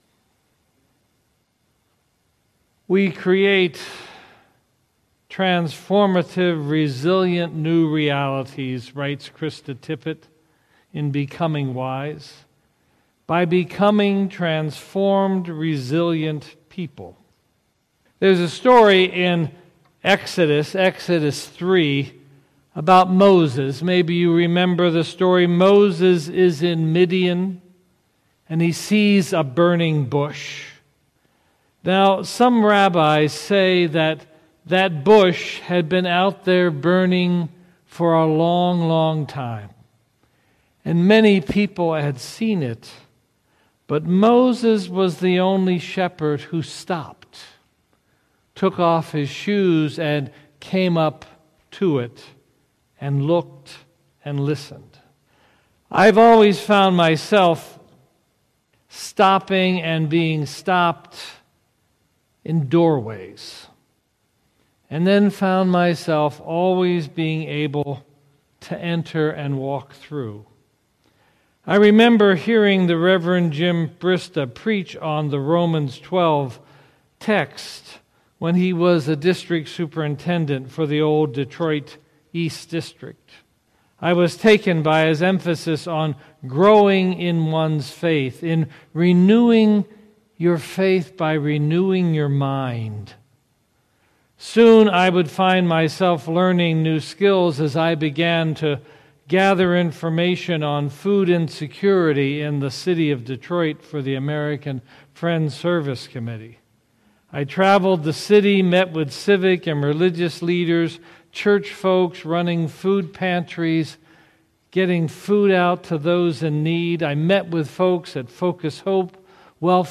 March 18, 2018 Sermon, "The Great Turning" • Nardin Park Church